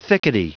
Prononciation du mot thickety en anglais (fichier audio)
Prononciation du mot : thickety